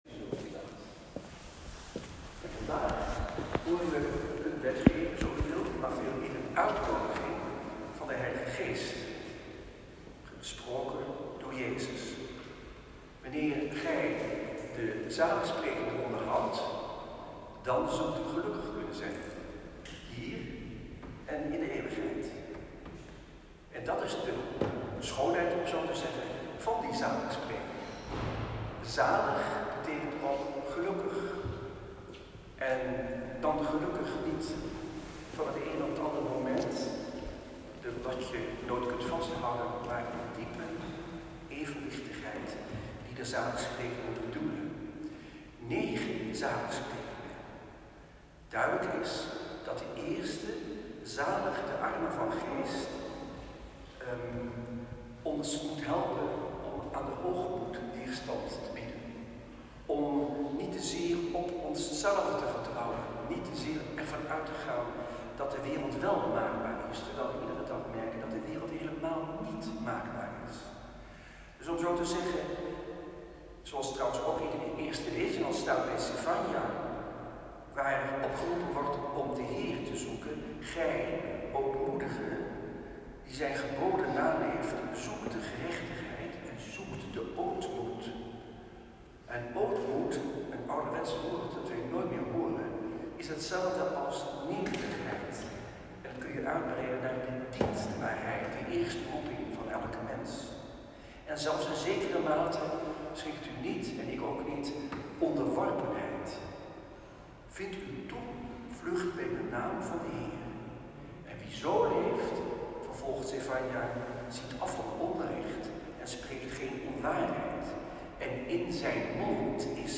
Vierde zondag door het jaar A. Celebrant Antoine Bodar.
Preek-2.m4a